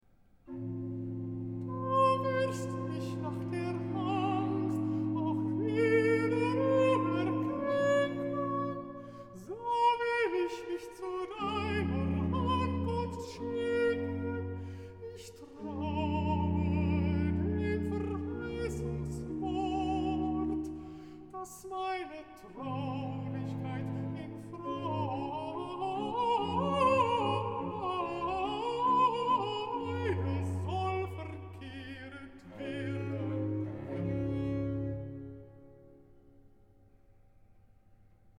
Второй речитатив – также простой, не аккомпанированный.
В полном соответствии с первым речитативом, где было на соответствующий мотив распето слово «скорбь», здесь симметрично распевается – на свой уже мотив, конечно – слово «радость», и тем самым подготовляется следующая ария, посвящённая уже этому новому аффекту.